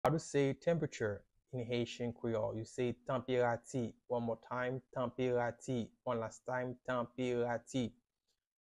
“Temperature” in Haitian Creole – “Tanperati” pronunciation by a native Haitian teacher
“Tanperati” Pronunciation in Haitian Creole by a native Haitian can be heard in the audio here or in the video below:
How-to-say-Temperature-in-Haitian-Creole-–-Tanperati-pronunciation-by-a-native-Haitian-teacher.mp3